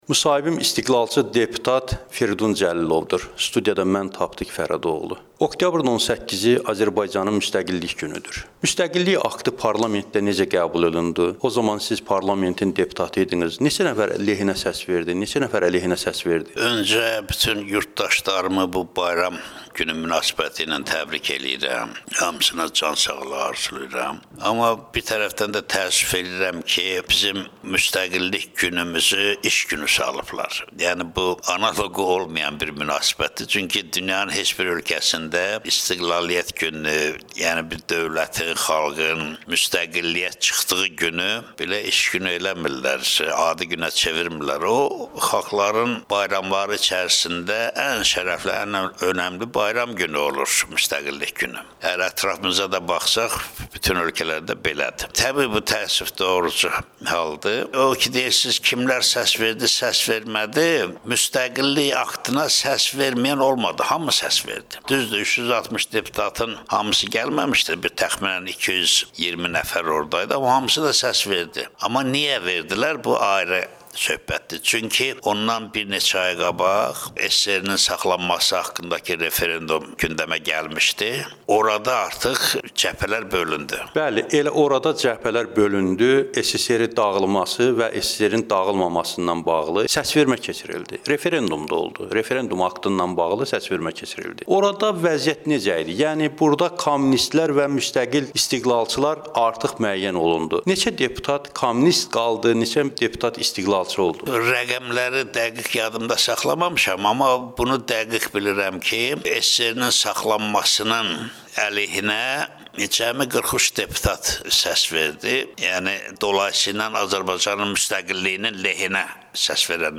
Firudin Cəlilovla müsahibə